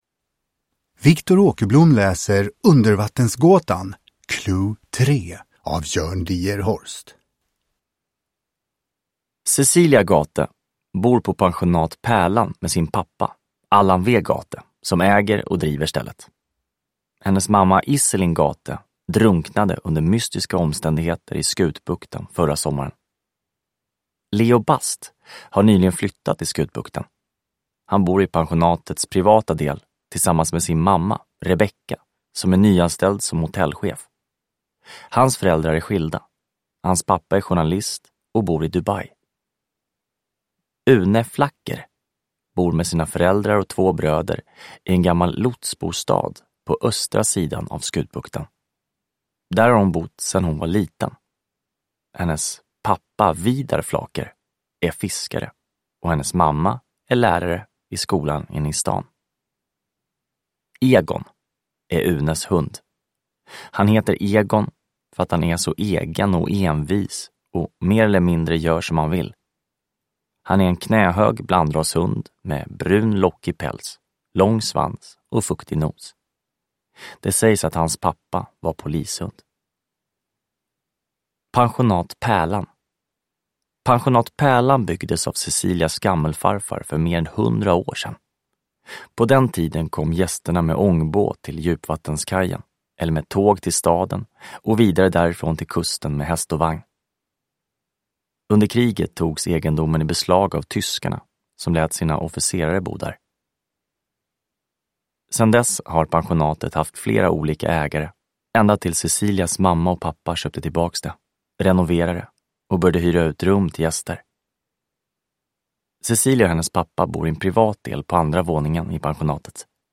Undervattensgåtan – Ljudbok – Laddas ner